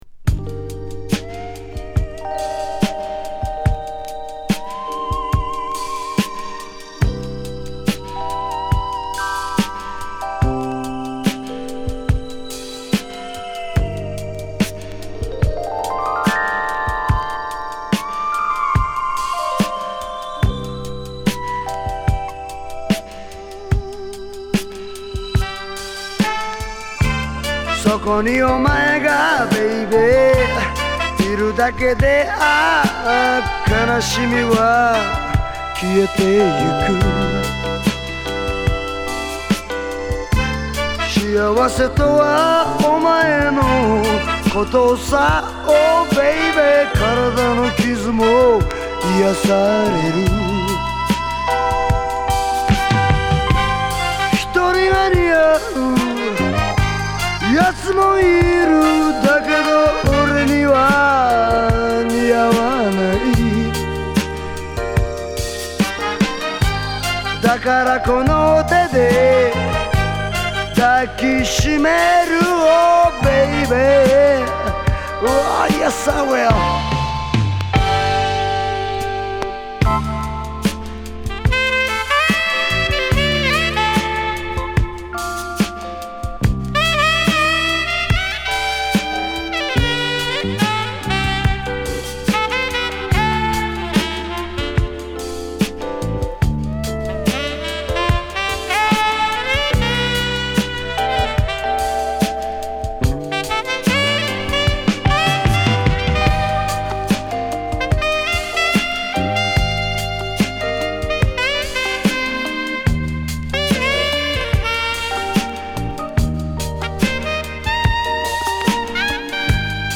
日本が世界に誇る和モノ・レアグルーヴ・サントラ最高峰盤